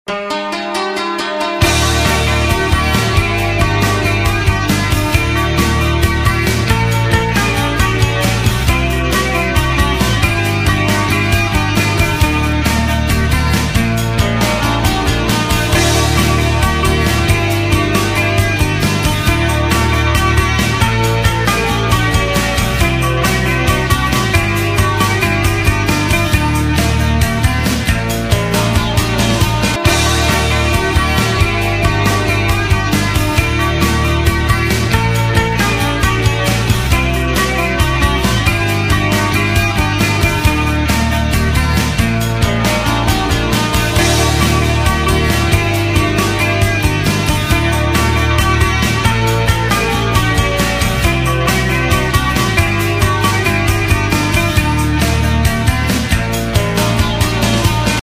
• Качество: 128, Stereo
гитара
громкие
красивые
без слов
инструментальные
Инструментальный проигрыш из композиции группы